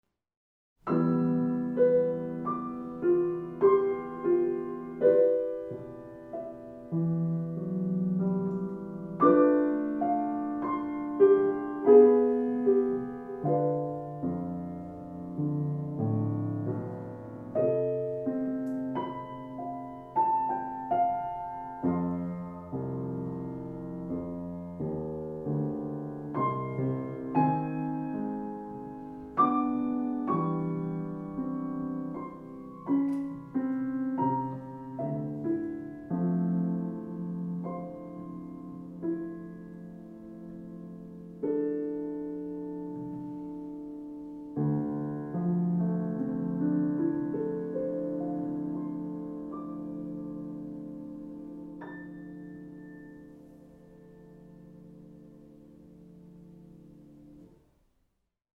fieberhaft
vereinigend